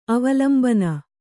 ♪ avalambana